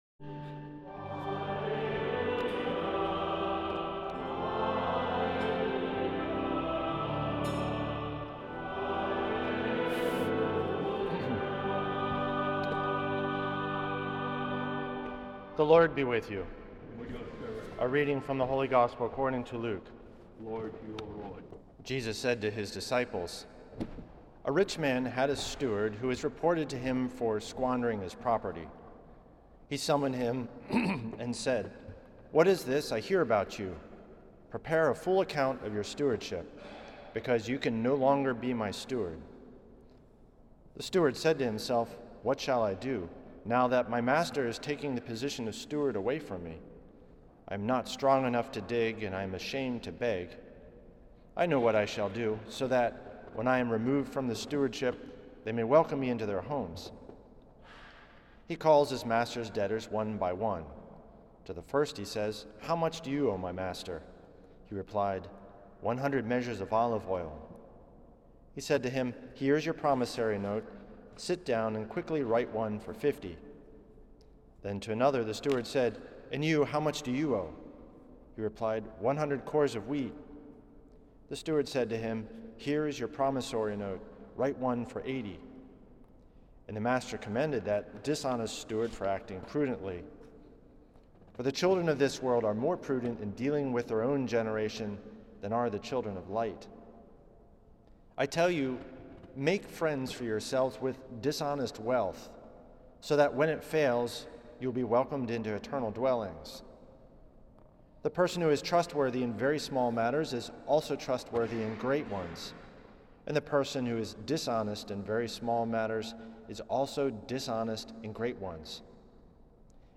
at St. Patrick’s Old Cathedral in NYC